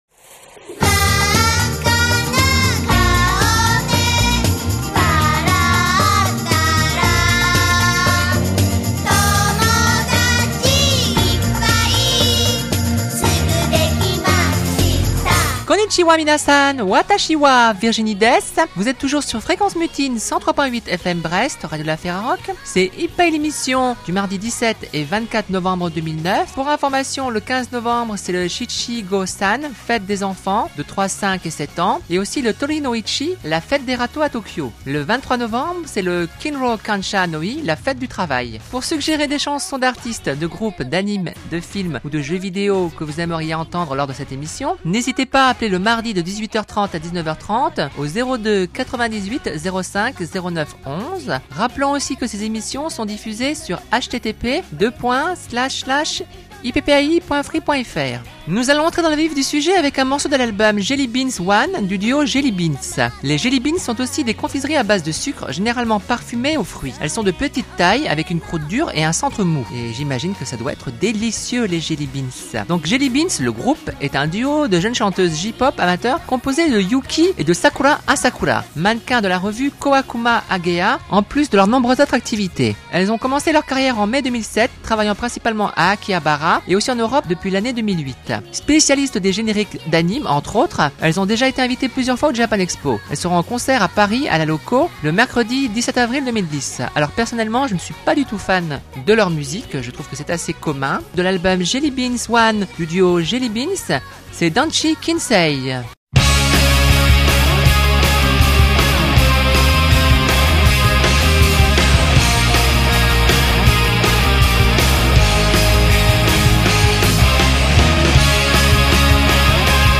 On y découvre alternativement des morceaux d'Asian musique ou de Visual, des génériques d'Animes et parfois de films et de jeux vidéos. Chaque morceau est lancé avec de courtes présentations des artistes, groupes, mangas, animes, jeux vidéos ou films.